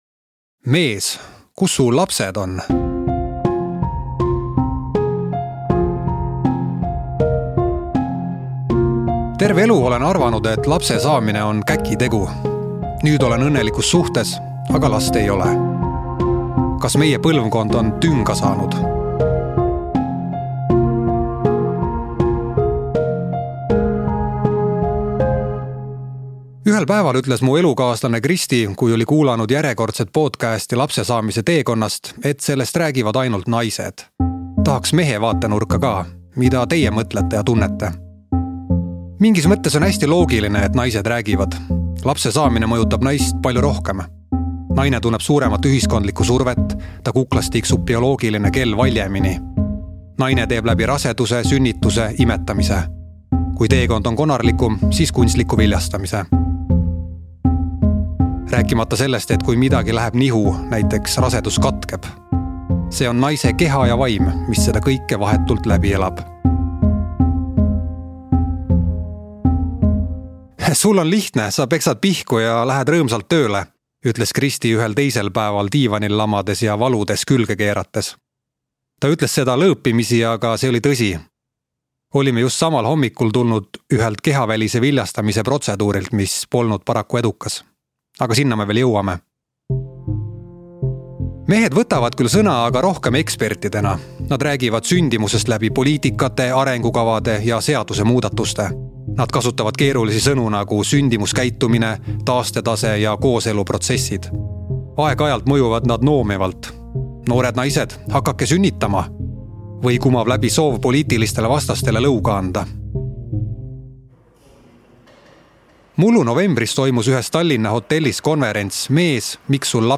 Audiolugu loeb